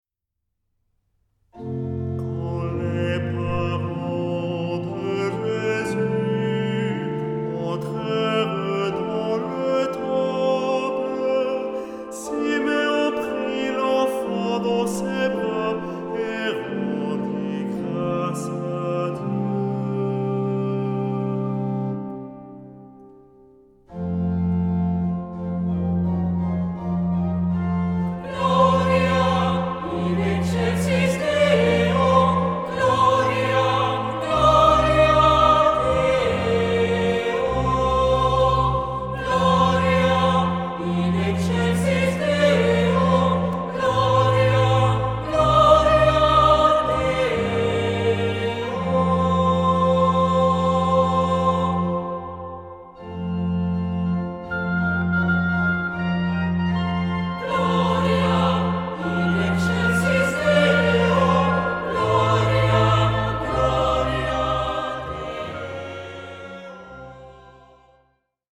Genre-Style-Form: troparium ; Psalmody ; Sacred
Mood of the piece: collected
Type of Choir: SAH OR SATB  (4 mixed voices )
Instruments: Organ (1) ; Melody instrument (optional)
Tonality: F major